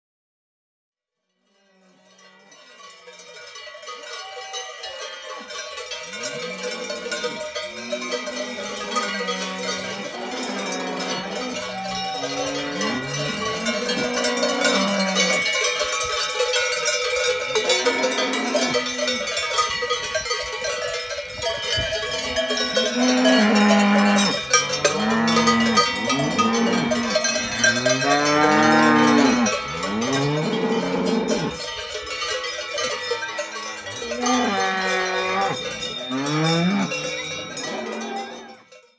Bio-Natur-Klingeltöne
Allgemeiner Klingelton, 40 Sek. zunehmende Lautstärke